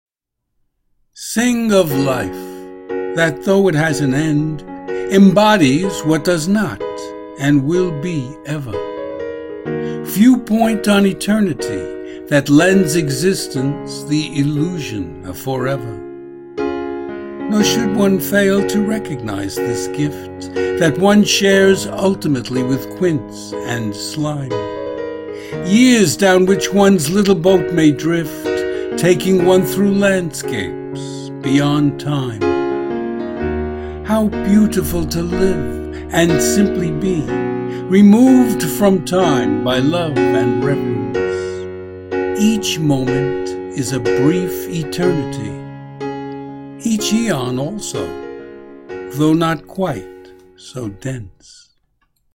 Audio and Video Music: